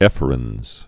(ĕfər-ənz, -ə-rĕnz)